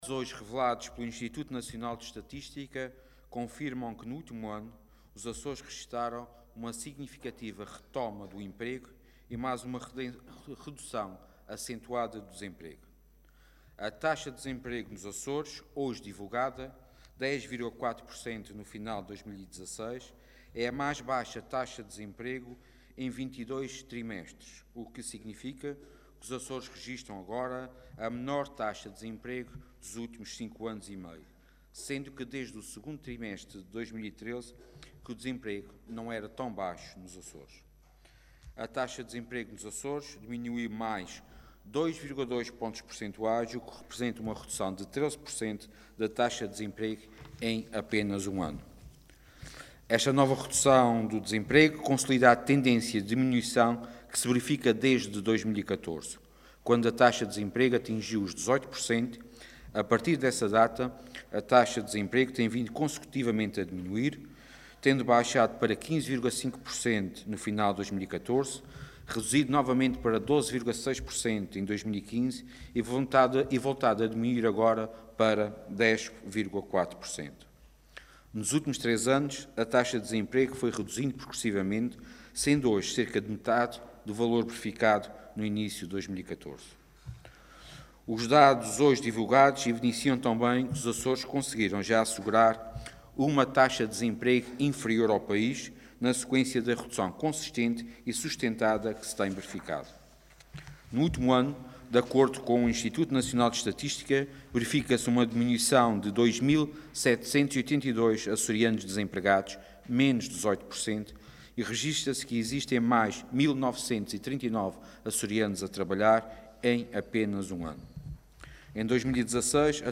Intervenção do Vice-Presidente do Governo